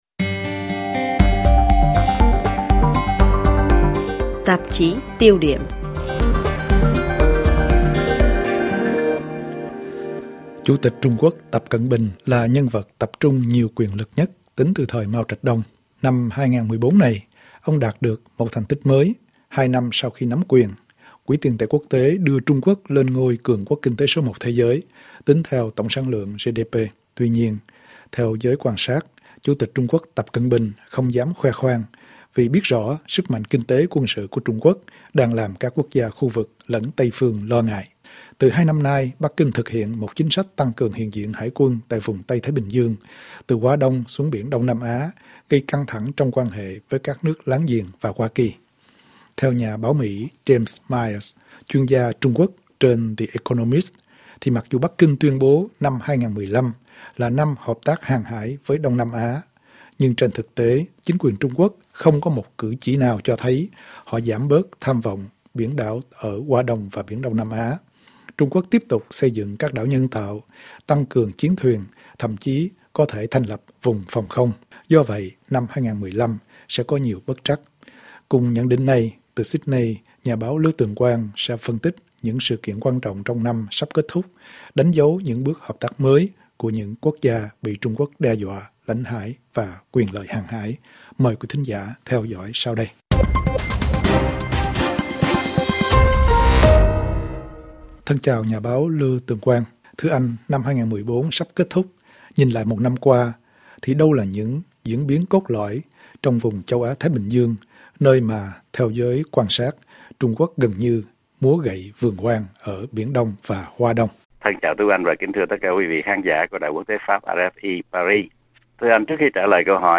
Liệu lời hứa của Tập Cận Bình có được các nước trong vùng tin tưởng hay không ? RFI đặt câu hỏi với nhà báo